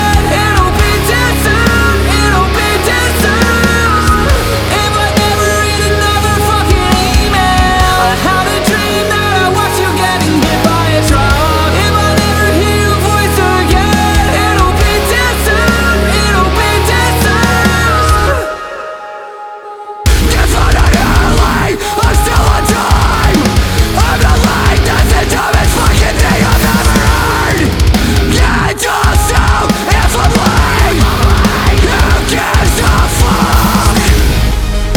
Жанр: Рок / Метал